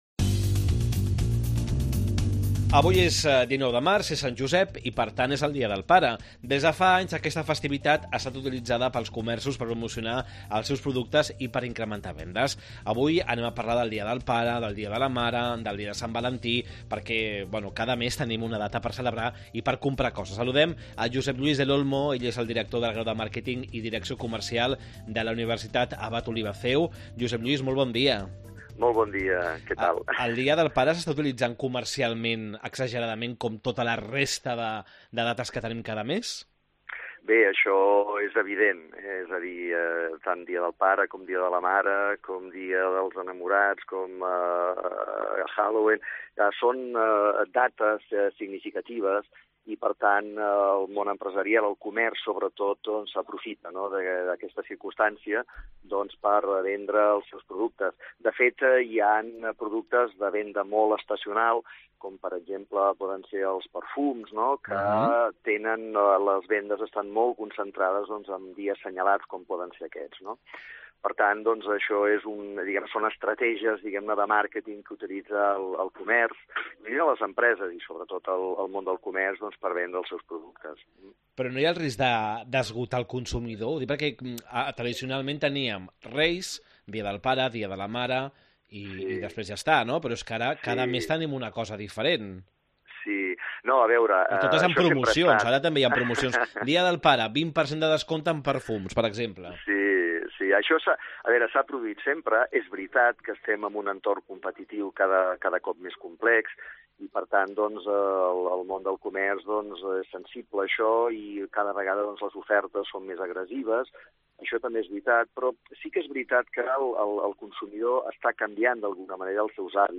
Com afronten els comerços la seva estratègia comercial? Entrevista